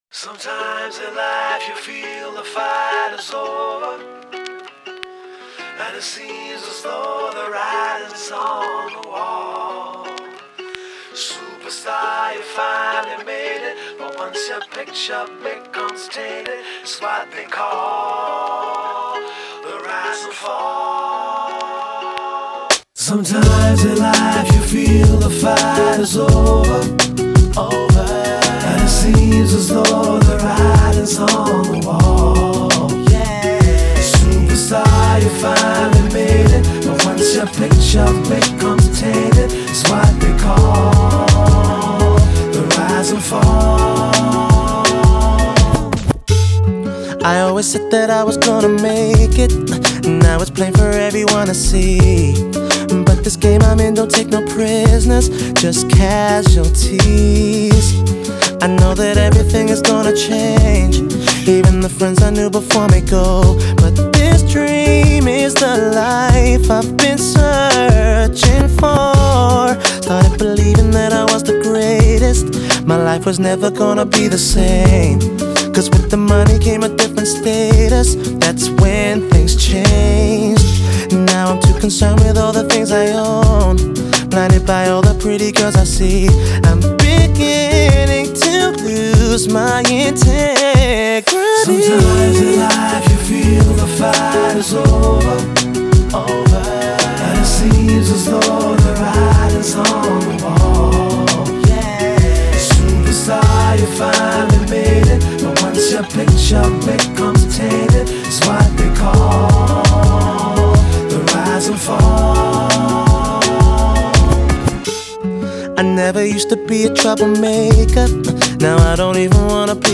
Genre: New Wave